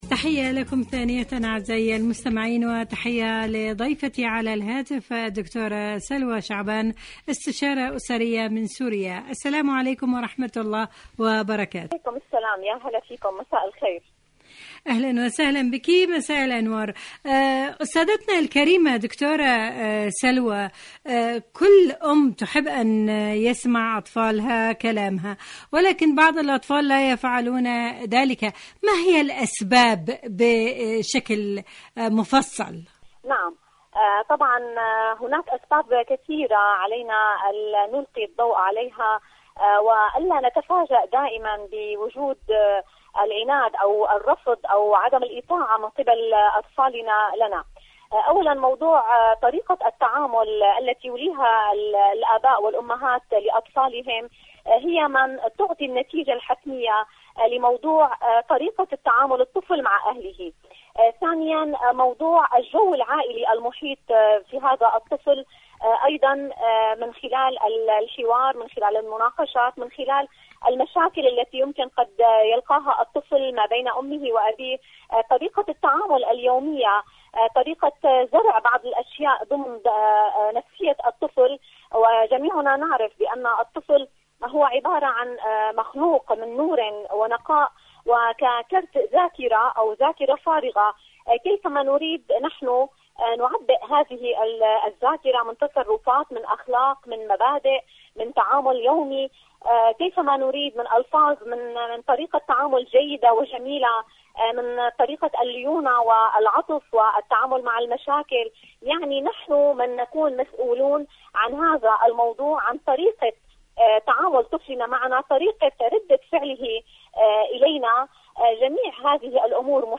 مقابلات مقابلات إذاعية برامج إذاعة طهران العربية برنامج عالم المرأة المرأة الأطفال كيف أجعل أطفالي يطيعوني؟